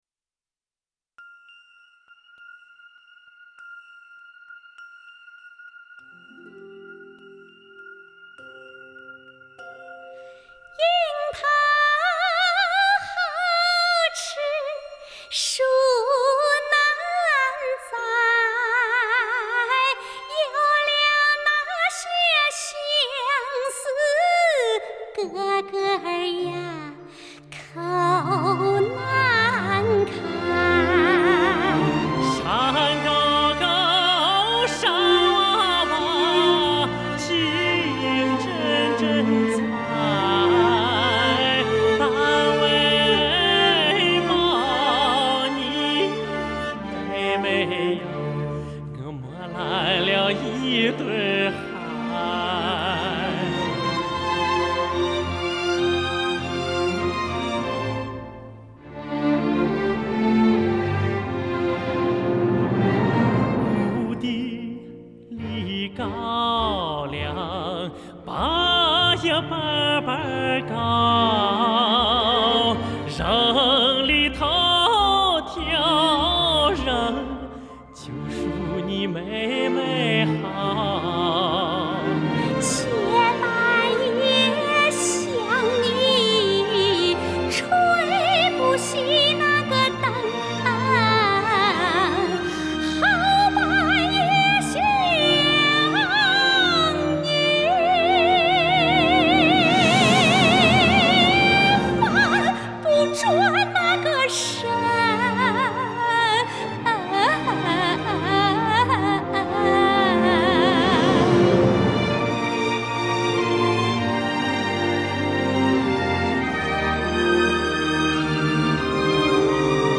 山西左权民歌